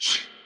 5 Harsh Realm Vox Sweep Reverb.wav